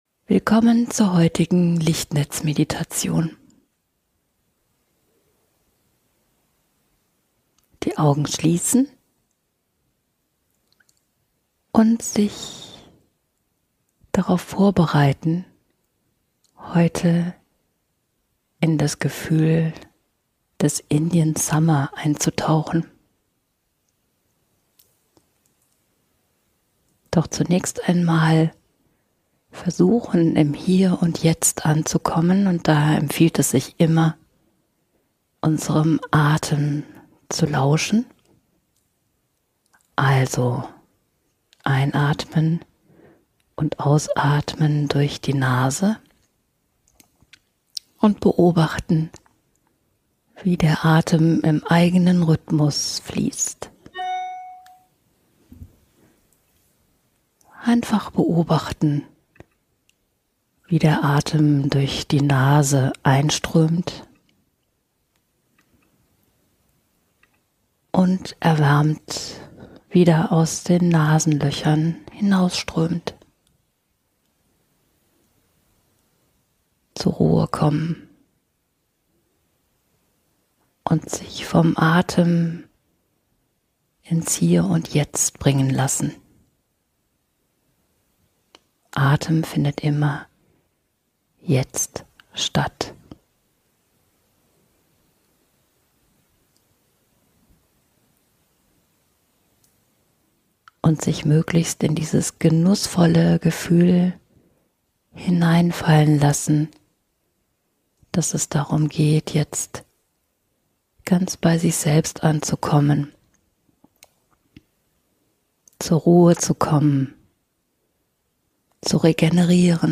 Transformierende Herbstmeditation ~ Lichtnetz-Meditationen Podcast